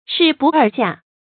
市不二价 shì bù èr jià
市不二价发音